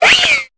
Cri de Mistigrix dans Pokémon Épée et Bouclier.